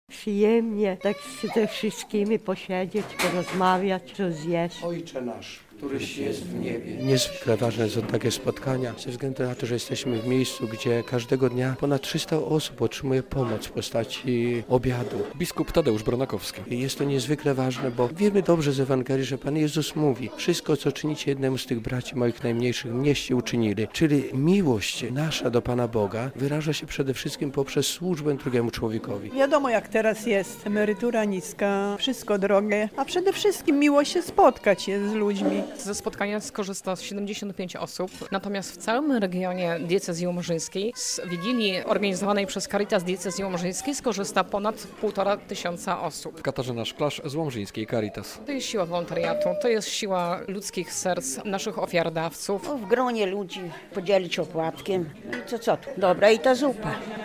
Spotkanie wigilijne w kuchni Caritas Diecezji Łomżyńskiej - relacja